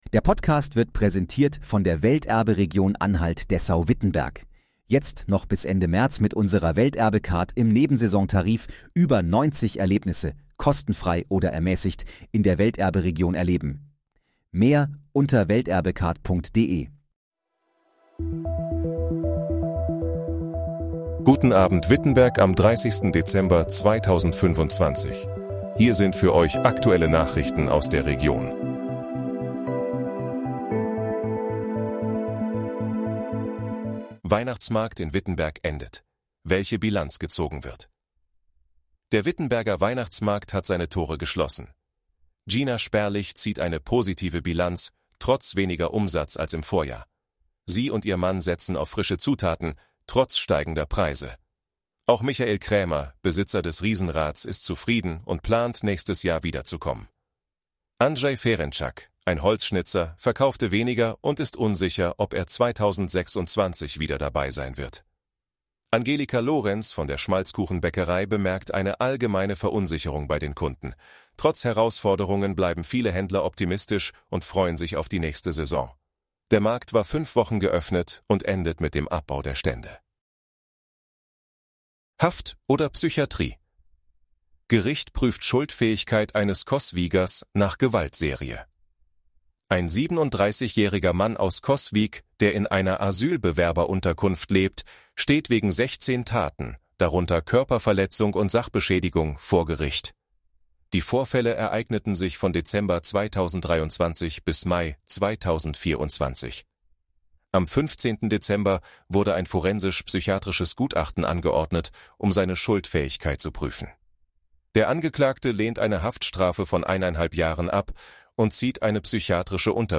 Guten Abend, Wittenberg: Aktuelle Nachrichten vom 30.12.2025, erstellt mit KI-Unterstützung
Nachrichten